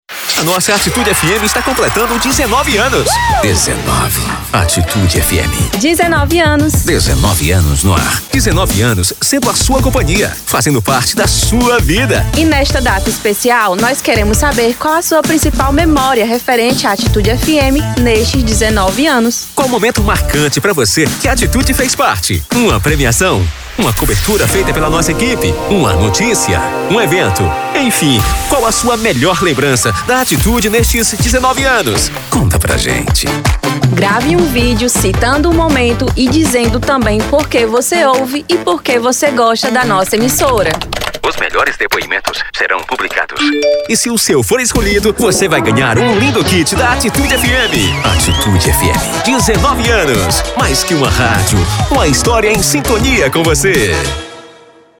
Chamada de Rádio - Alegre/jovem: